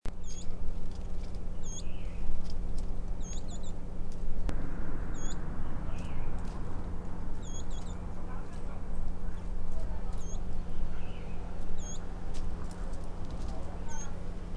29-4東埔2012mar26灰頭花翼地面覓食叫2.mp3
紋喉雀鶥 Alcippe cinereiceps formosana
南投縣 信義鄉 東埔
錄音環境 草叢
行為描述 兩隻覓食